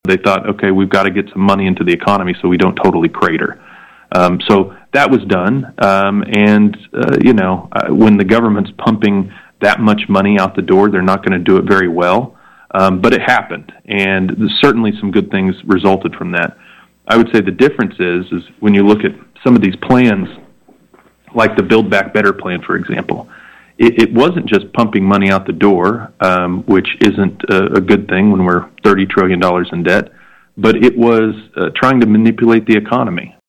LaTurner joined KVOE’s Morning Show on Wednesday, in part to introduce himself to residents in Lyon, Chase, Morris and Wabaunsee counties and in part to talk about issues like abortion rights, civil rights and inflation impact.